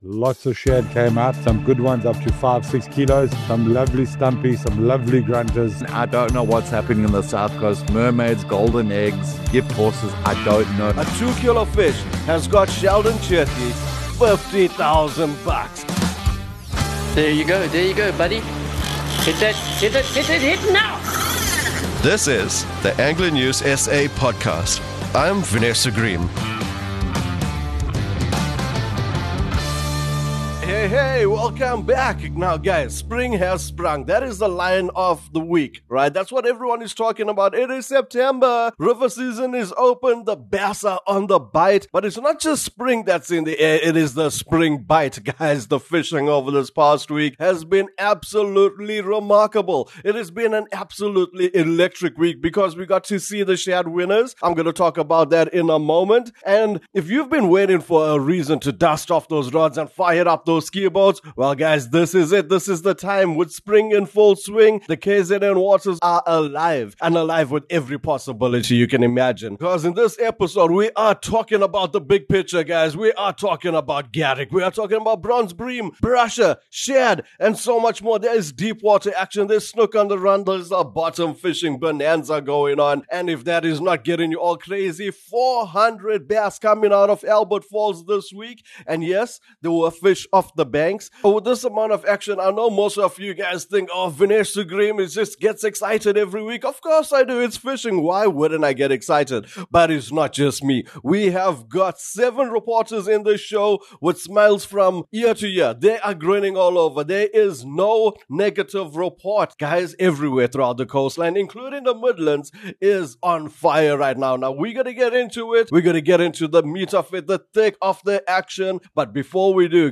From Durban to the North Coast, South Coast to the Midlands, and all the way to Albert Falls, our reporters have confirmed it’s firing everywhere.